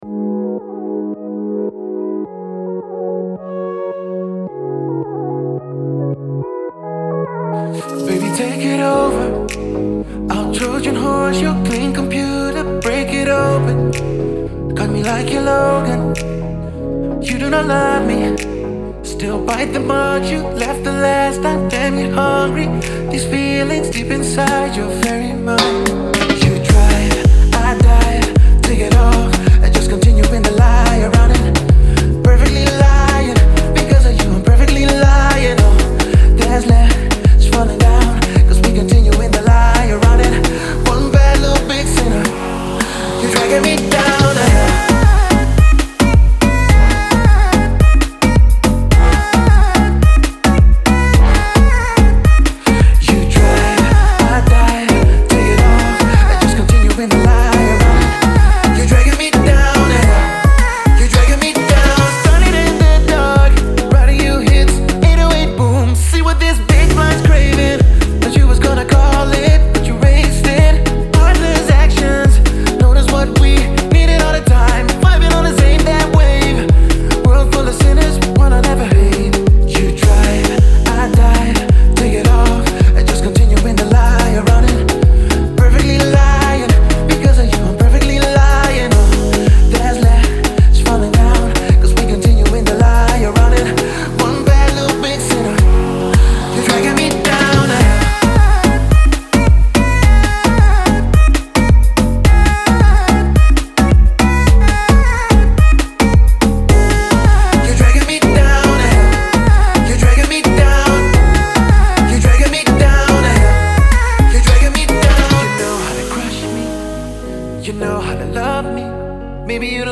энергичная песня